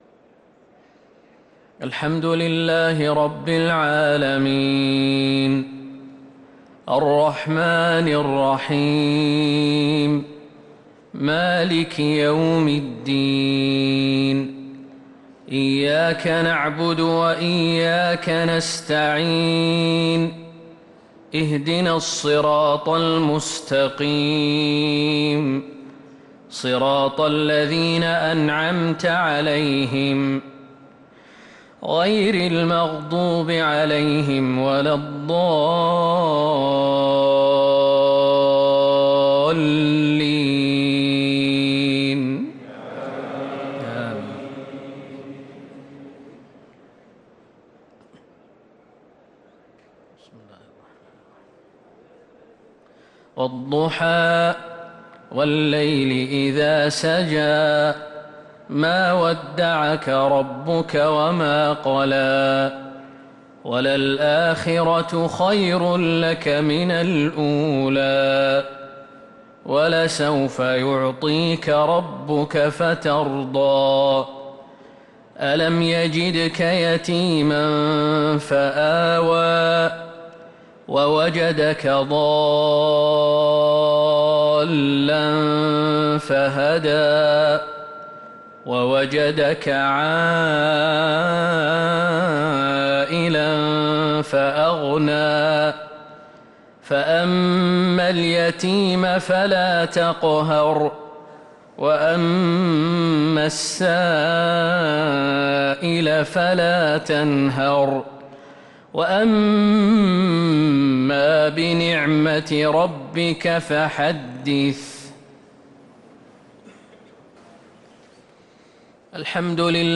صلاة المغرب للقارئ خالد المهنا 21 ذو القعدة 1444 هـ